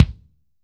B.B KICK 7.wav